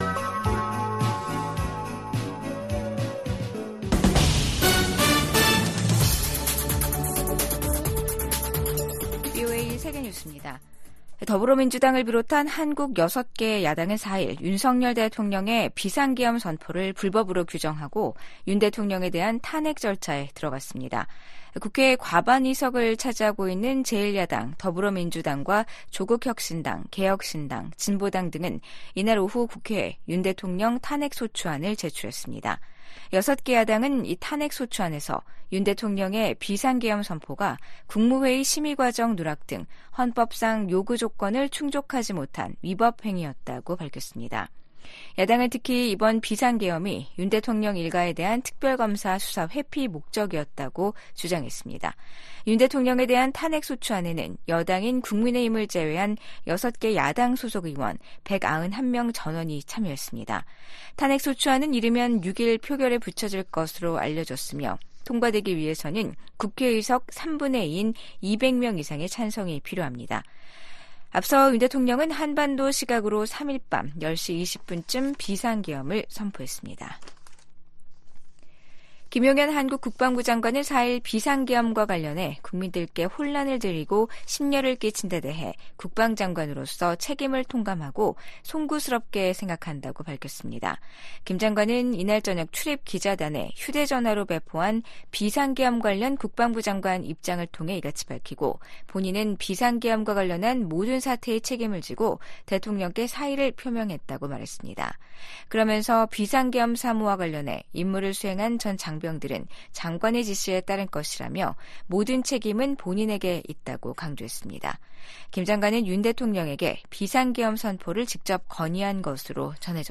VOA 한국어 아침 뉴스 프로그램 '워싱턴 뉴스 광장'입니다. 윤석열 한국 대통령의 비상계엄 선포가 6시간 만에 해제됐지만 정국은 한 치 앞을 내다볼 수 없는 혼돈으로 빠져들고 있습니다. 야당은 윤 대통령의 퇴진을 요구하는 한편 탄핵 절차에 돌입했습니다. 미국 정부는 윤석열 한국 대통령이 법에 따라 비상계엄을 해제한 데 대해 환영의 입장을 밝혔습니다.